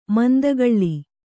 ♪ mandagaḷḷi